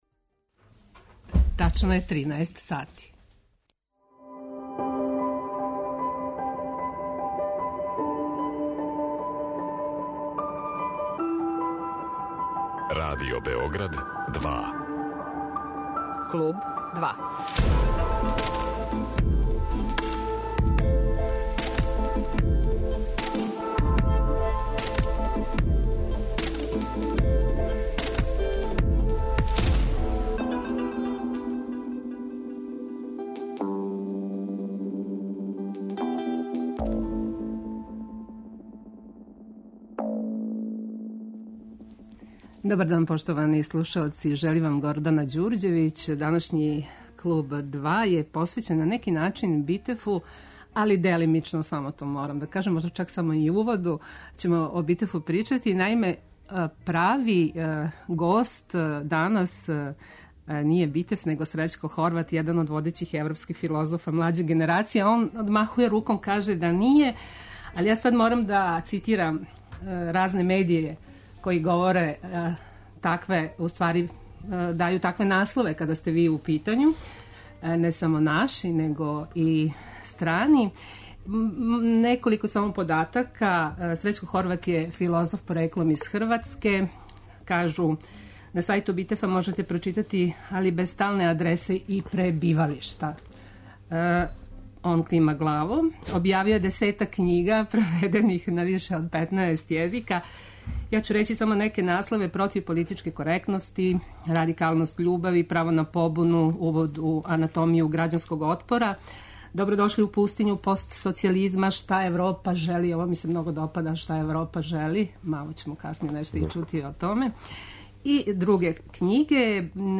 Гост Клуба 2 је Срећко Хорват, један од водећих европских филозофа млађе генерације.